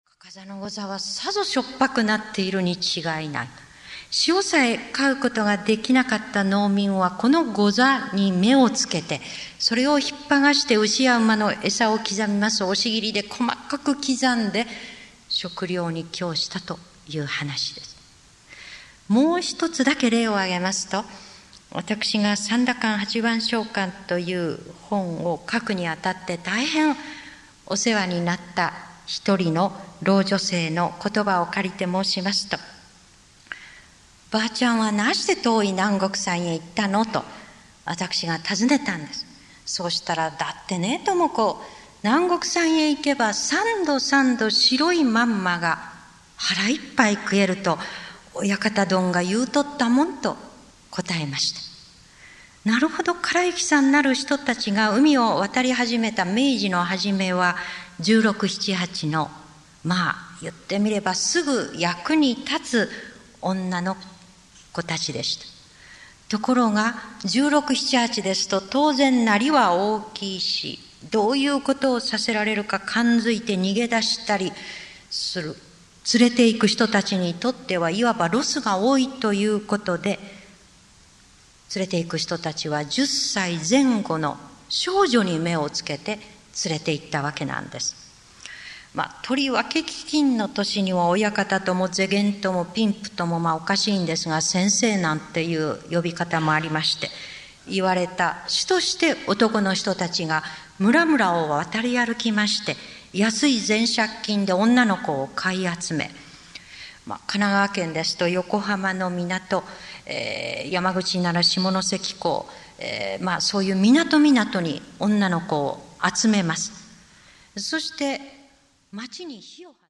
名だたる文筆家が登場する、文藝春秋の文化講演会。
からゆきさんのお婆ちゃんや貧民街の娼婦たち。性がもたらす悲しみについて、山崎が凛と語る。
（1988年9月14日 鳥取市民会館 菊池寛生誕百周年記念講演会より）